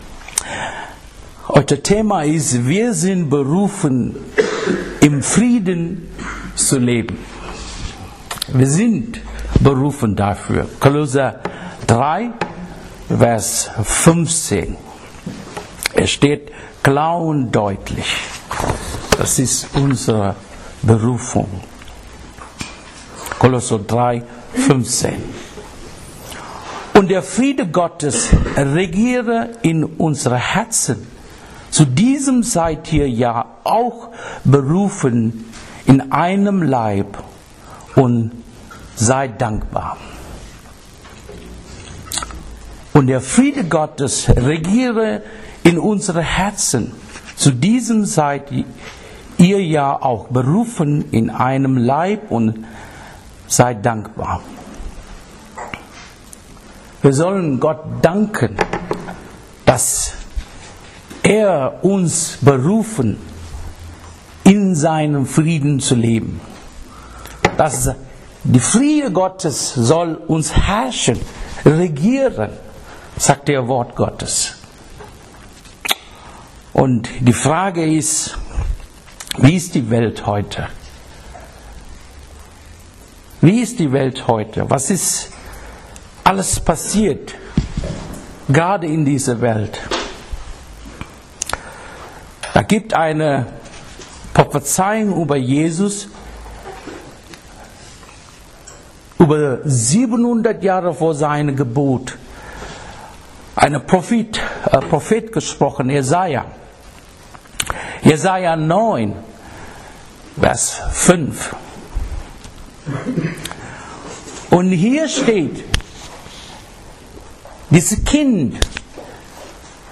Evangelische Gemeinde Gevelsberg e.V. - Predigten